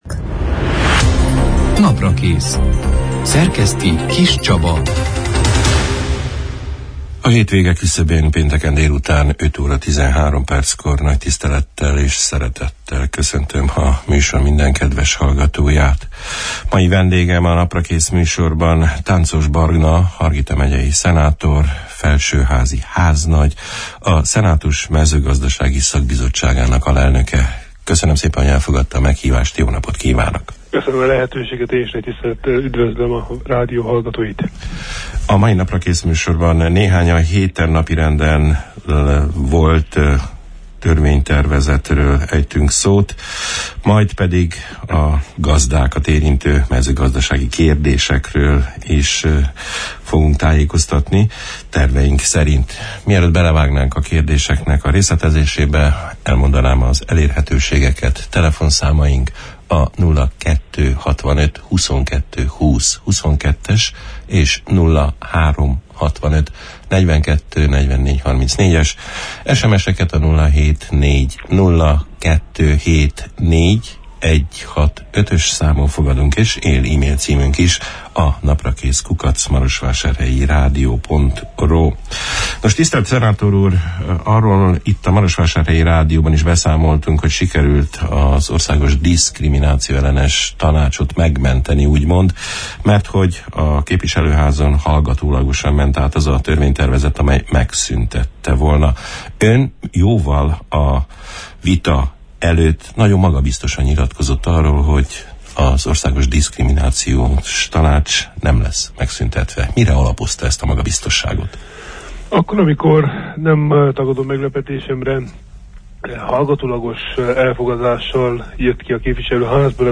A diszkrimnációellenes tanács létének megmentéséről, a papnevelő intézet állami támogatásának eléréséről, a kezdő kis- és közepes vállalkozásokat támogató program lényegéről, a gazdákat érintő törvényhozási tevékenységről beszélgettünk a március 17 – én, pénteken elhangzott Naprakész műsorban Tánczos Barna Hargita megyei szenátorral, a felsőház háznagyával, a mezőgazdasági szakbizottság alelnökével.